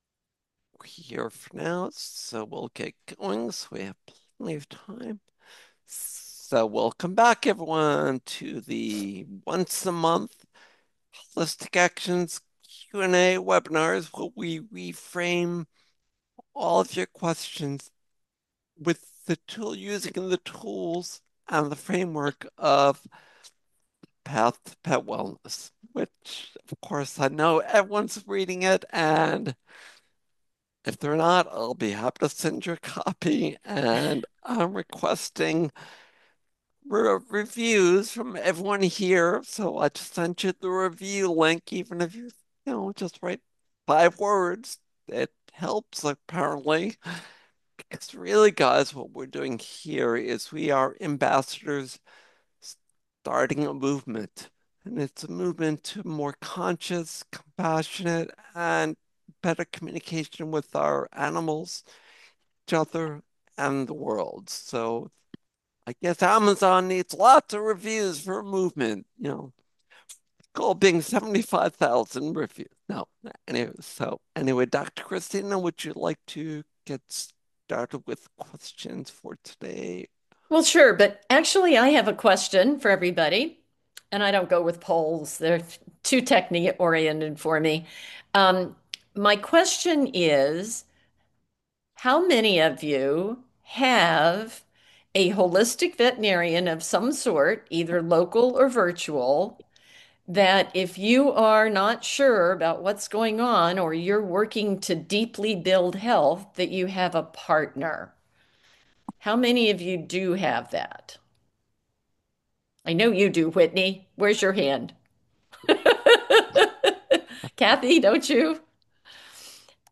Ask A Vet – Live Q&A 02/26/26 - Holistic Actions!